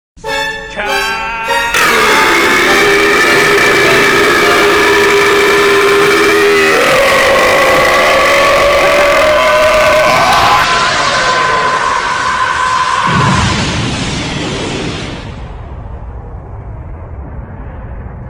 Goku Burp Charge: Instant Play Sound Effect Button